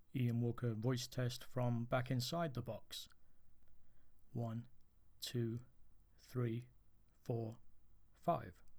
Number 1 is the microphone on the stand that came with it, not quite the centre of the room but certainly outside and away from my box and with me just holding the pop filter.
The voice is more open and natural and much of the boom goes away.
Whatever you did still sounds like talking in a box.
The input gain is still very low.
There is definitely more boominess in the recording inside the box.
The fan you heard was because the Snowball was much closer to the (very quiet) laptop.